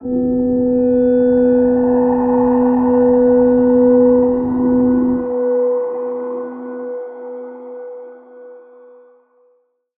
G_Crystal-B4-mf.wav